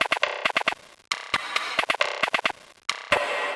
TM88 LoopCrazyPerc.wav